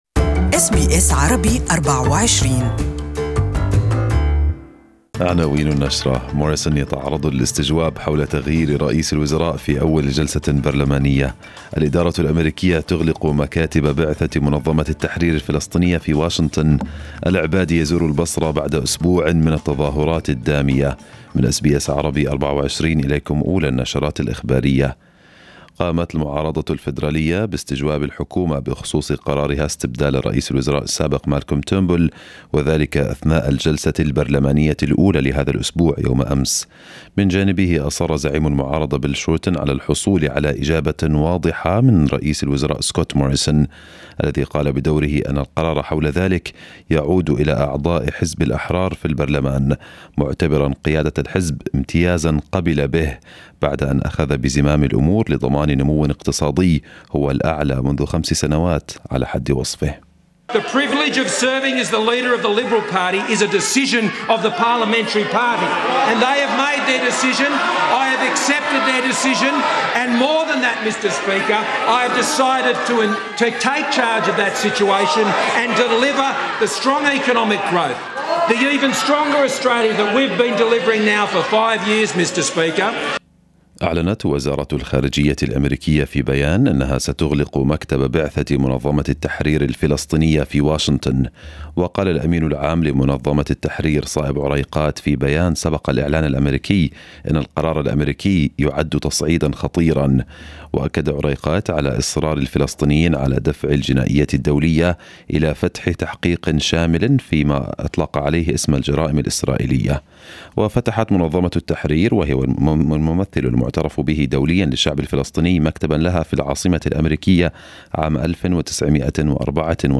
Listen to the full news bulletin in Arabic above Share